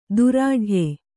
♪ durāḍhye